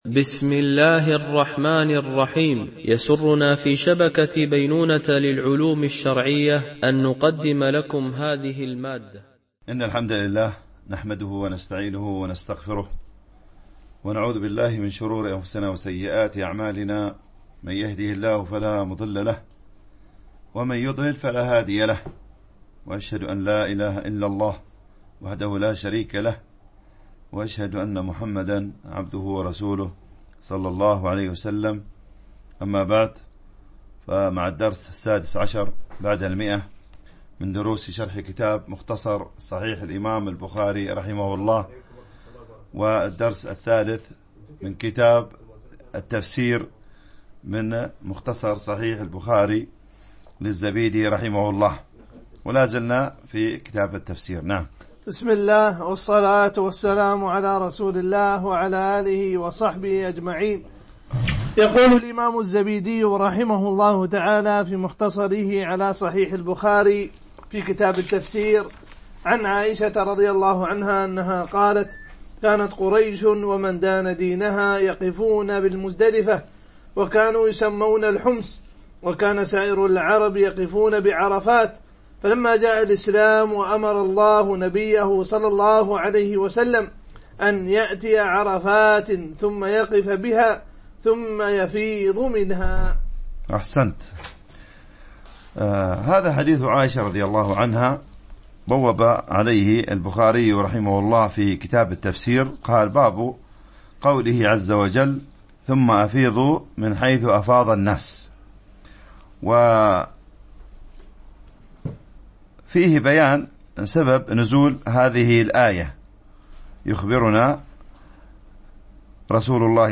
شرح مختصر صحيح البخاري ـ الدرس 116 ( كتاب التفسير ـ الجزء الثالث ـ الحديث 1720 - 1725 )